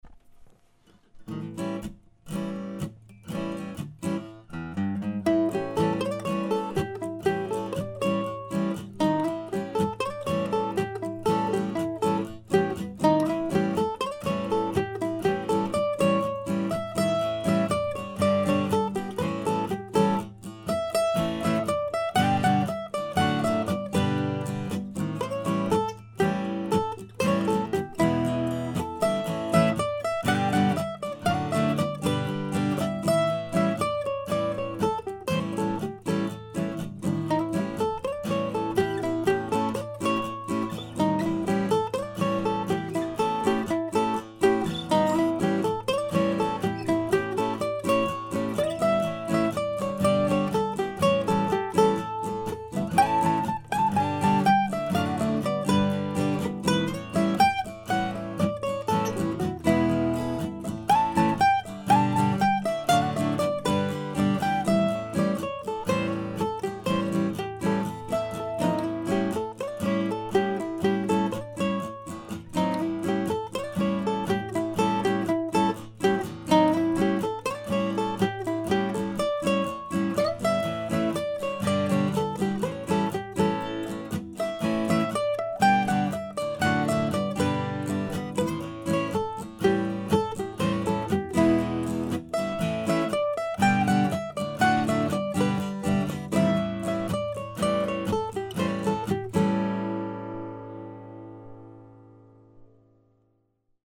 In any event this is a fun tune to play. It's written out here as a 16 bar tune but it is perfectly OK to make it an AABB tune instead. The recording features the first use of my new bottom-of-the-line Taylor guitar.